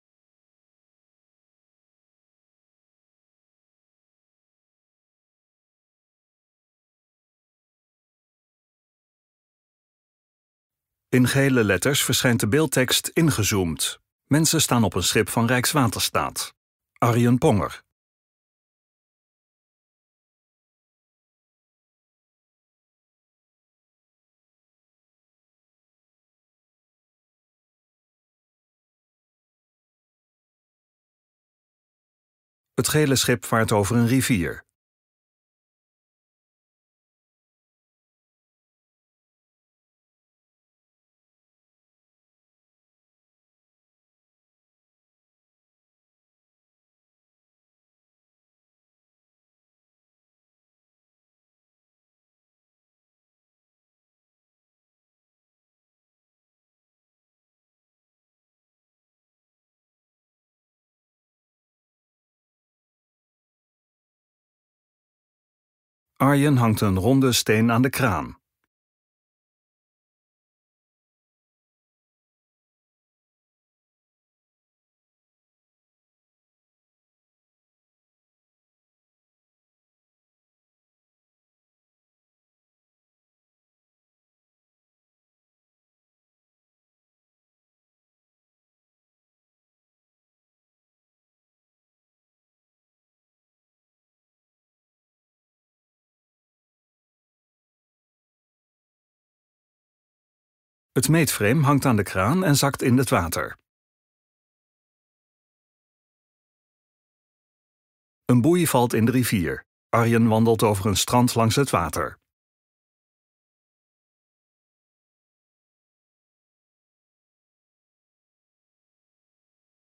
Vlog 19 februari 2023